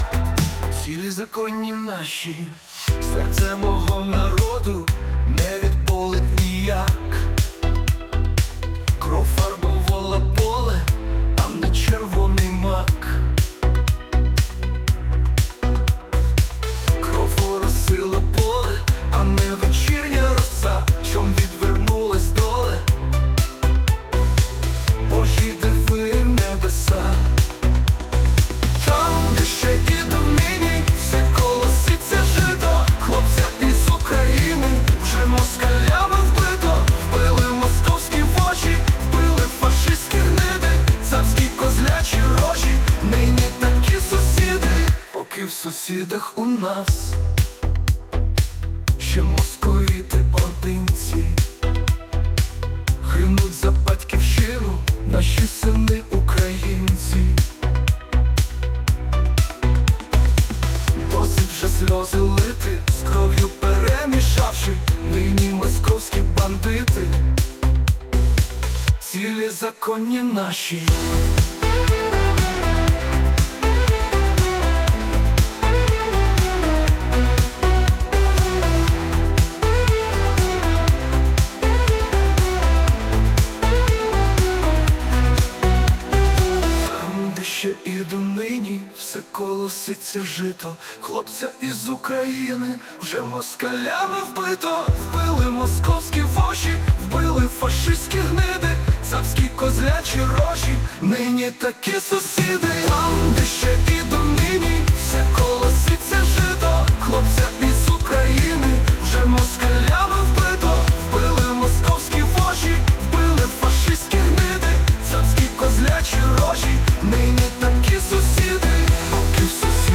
ТИП: Пісня
СТИЛЬОВІ ЖАНРИ: Ліричний